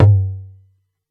PERC - TIME.wav